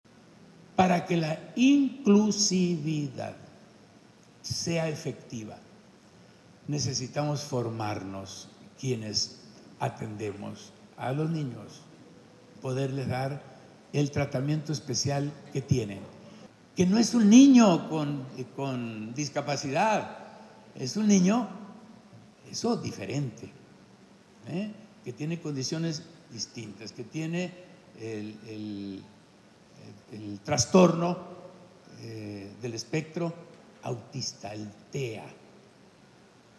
El mandatario estatal asistió a la presentación del libro “Autismo, de la bruma a la esperanza”.
CITA-1-AUDIO-GOBERNADOR-RRM-PRESENTACION-DEL-LIBRO-AUTISMO-DE-LA-BRUMA-A-LA-ESPERANZA-.mp3